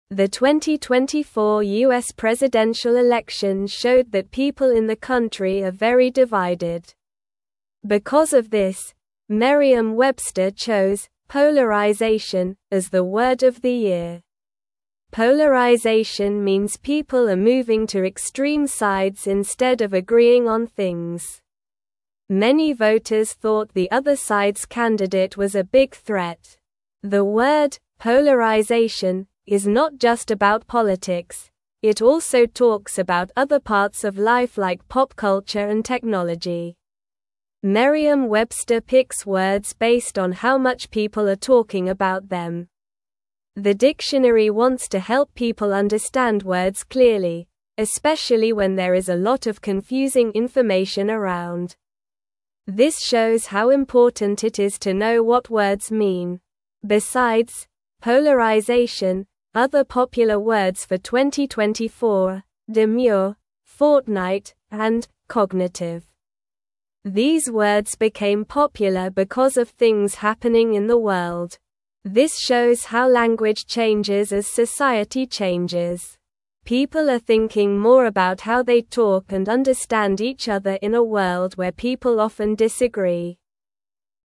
Slow
English-Newsroom-Lower-Intermediate-SLOW-Reading-People-Are-Divided-The-Word-of-the-Year.mp3